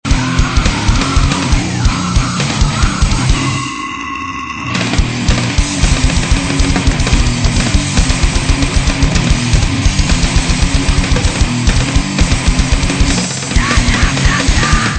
des musiques auto-produites
grindcore